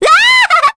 Requina-vox-Happy4_kr.wav